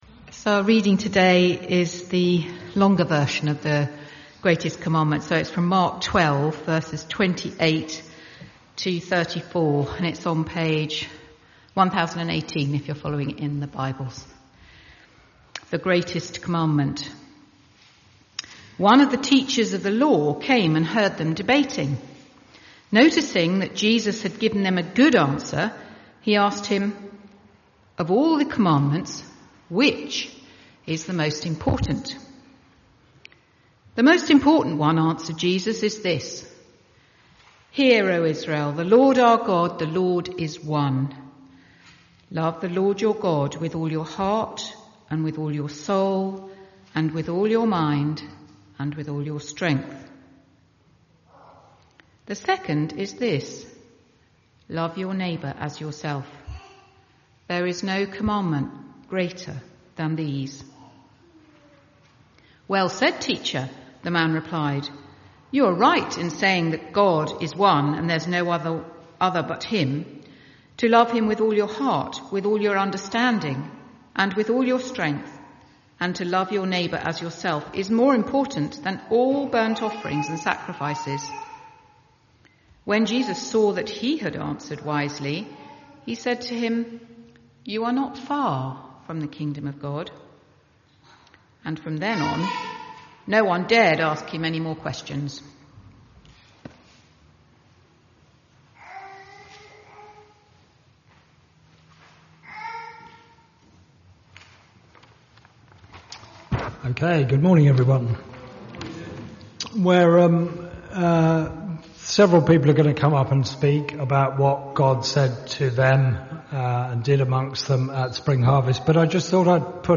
Instead of a sermon four church members who went to Spring Harvest spoke about what impact it had on them. This year's theme was 'No Greater Life'.